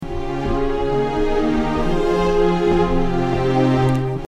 Это какие-то synthetic strings?